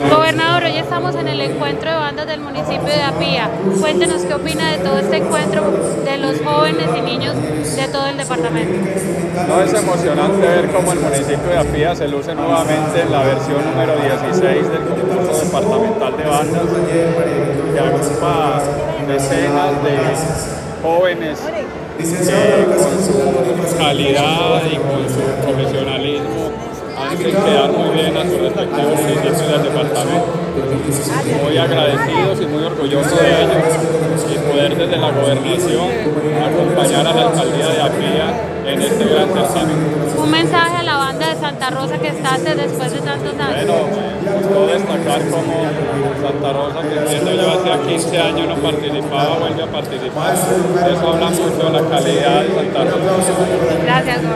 Audio: gobernador de Risaralda, Víctor Manuel Tamayo Vargas